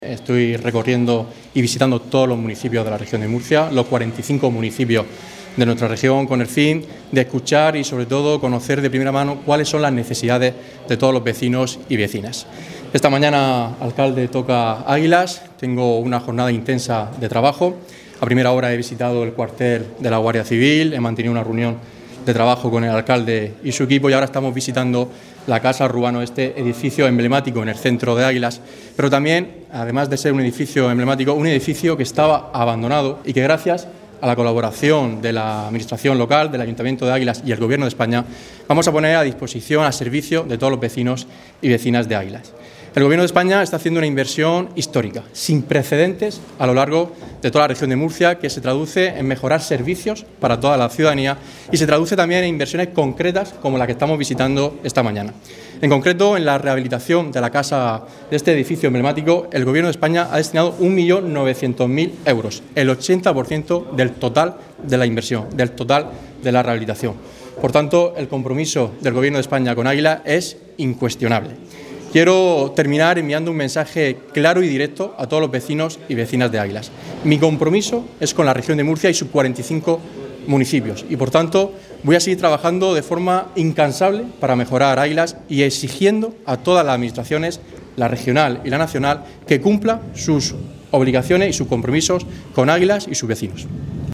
Declaraciones de Francisco Lucas